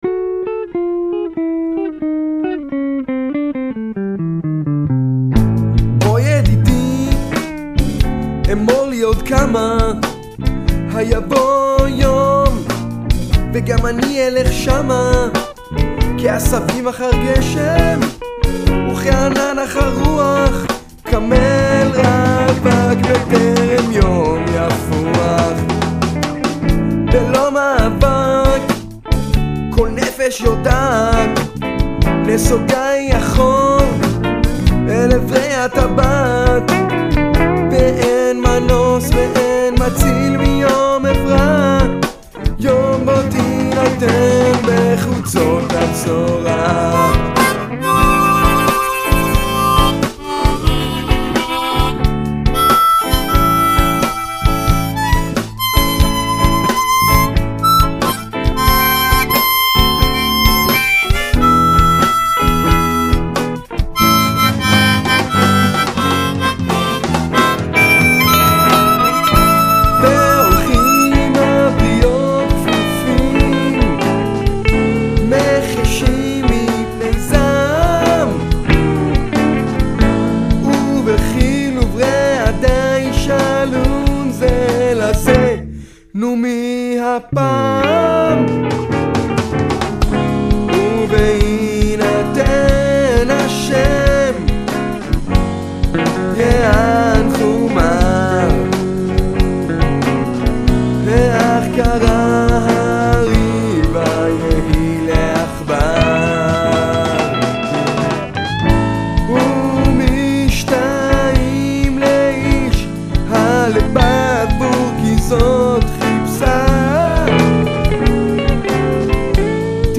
גיטרה,מפוחית,תכנות תופים,בס, ושירה: אני.
הלחן הוא לחן של בלוז.
אגב - שני הבתים הראשונים באמת זהים, אח"כ במפוחית האקורדים שונים, ובטח בשני הבתים הבאים - בהם יש גם שינוי סולם בטון וחצי למטה, בניגוד לעליית הטון בבתים הראשונים.
אדמין - איכות ההקלטה כאן היא של אולפן ביתי סמי-מקצועי.